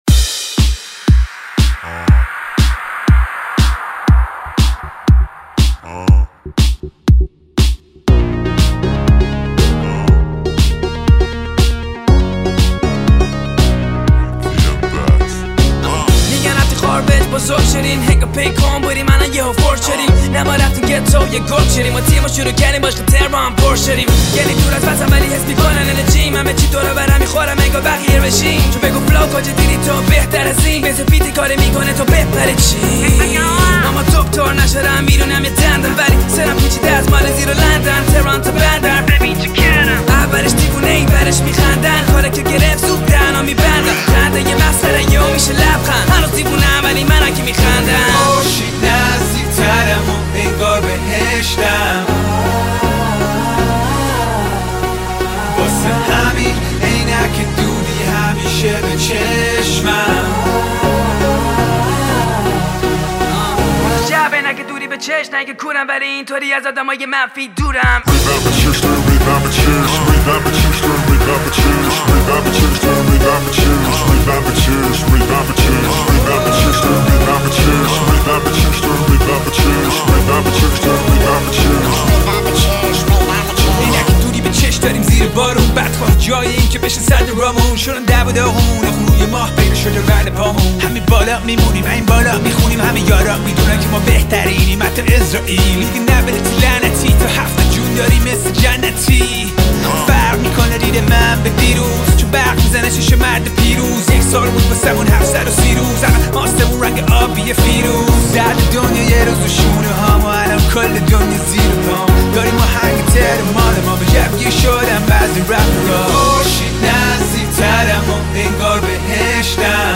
یکی از خفن ترین و شاد ترین آهنگ های گروه